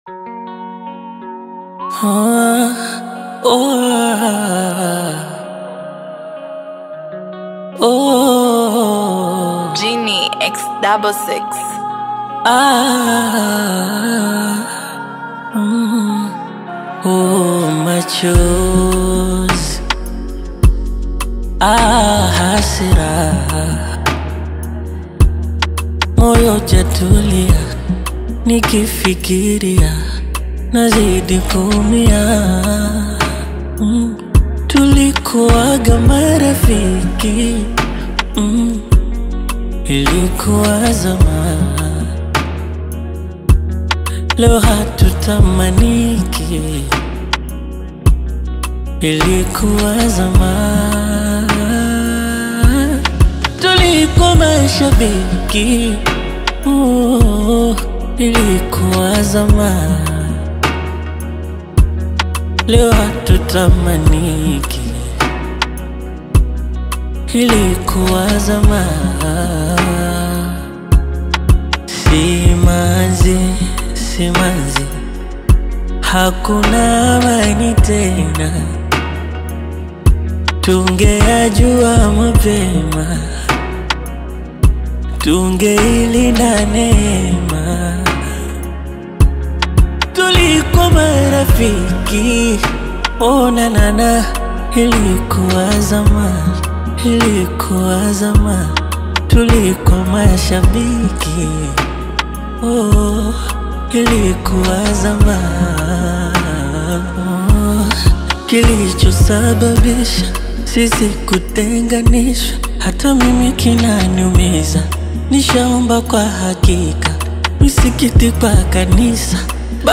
With its infectious beat and captivating vocals